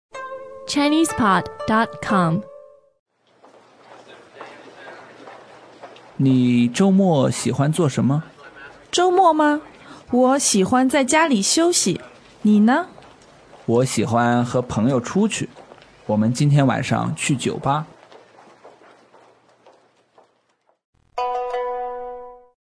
Dialogue MP3 - Newbie - Weekend Activities